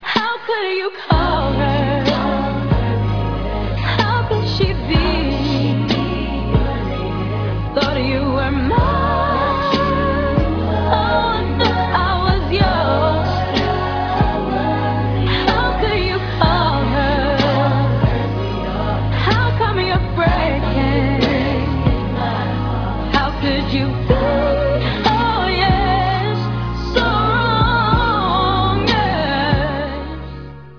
Written, produced and all synthesizers and keyboards